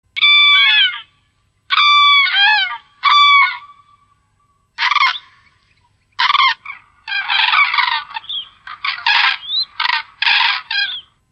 В тундре одна семья этих птиц занимает пространство в несколько квадратных километров, насколько хватает звука их голосов, звучащих в унисон.
Правильный ответ: Журавли